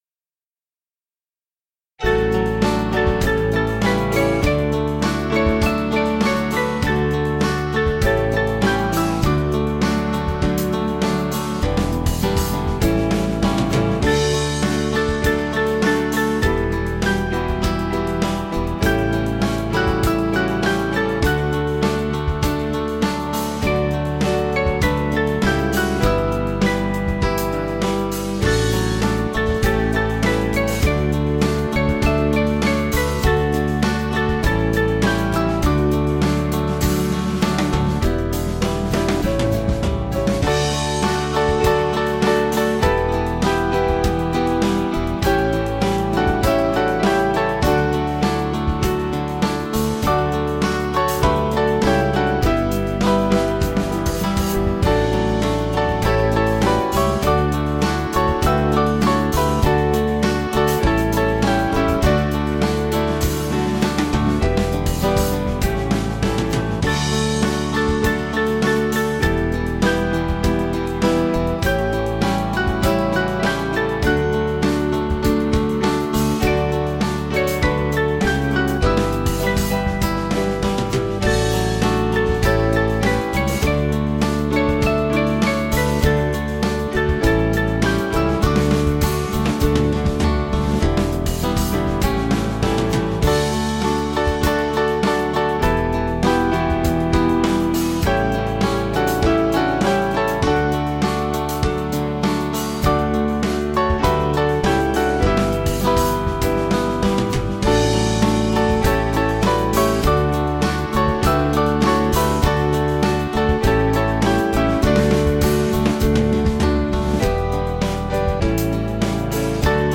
Malagasy melody
Small Band